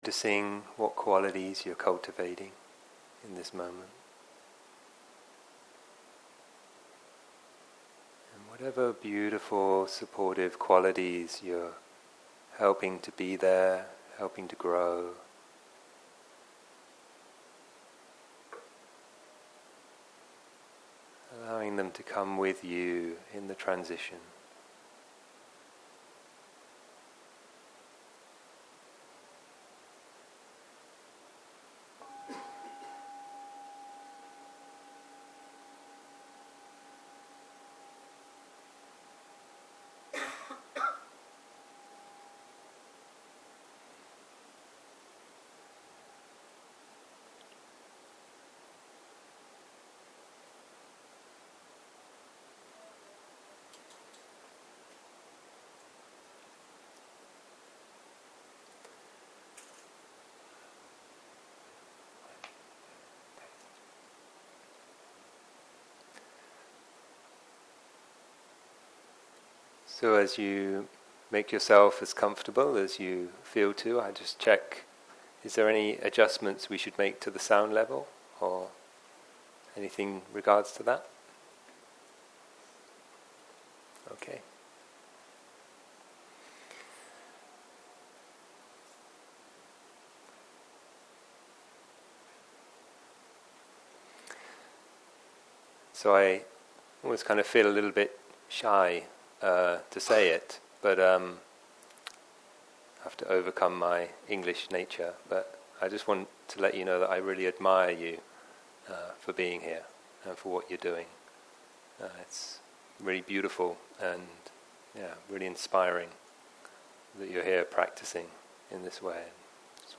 ערב - שיחת דהרמה - Unsatisfactory